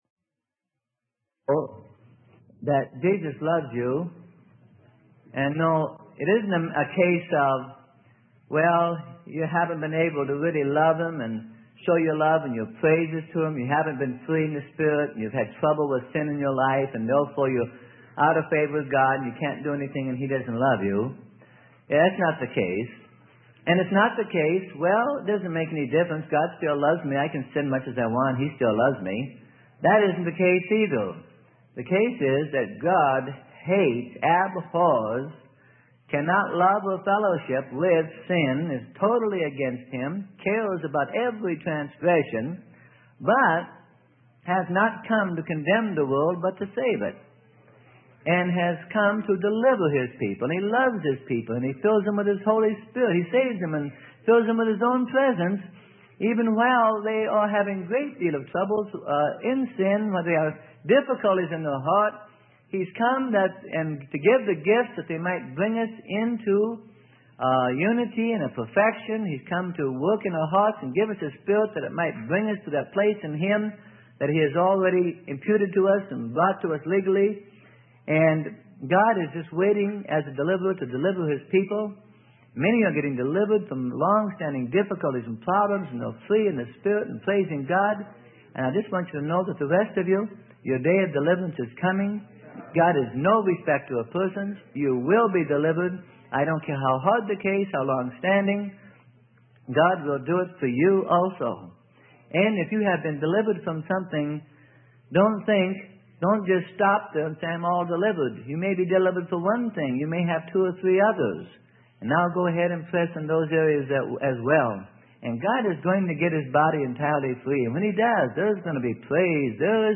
Sermon: Praying in Tongues and Interpretations - Part 1 - Freely Given Online Library